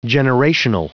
Prononciation du mot generational en anglais (fichier audio)
Prononciation du mot : generational